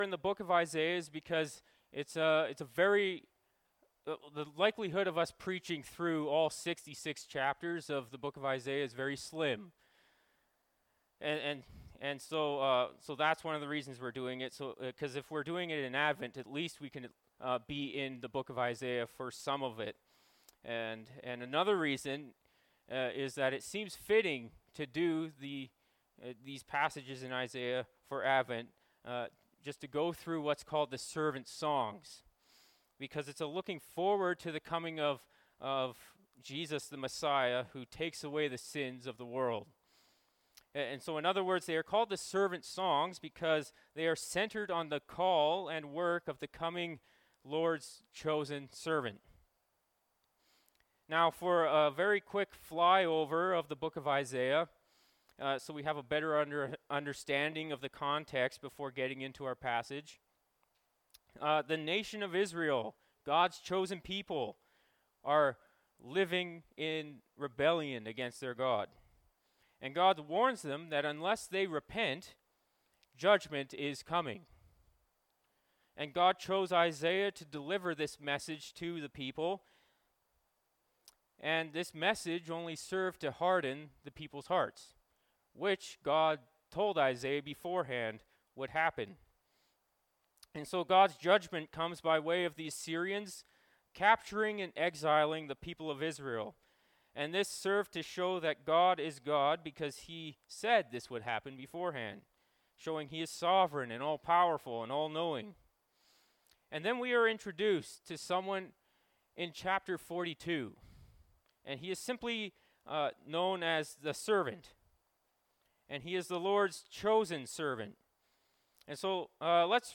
Nov 26, 2023 The First Servant Song (Isaiah 42:1-9) MP3 SUBSCRIBE on iTunes(Podcast) Notes Discussion Sermons in this Series This sermon was recorded in Grace Church Salmon Arm (and also preached in Grace Church Enderby).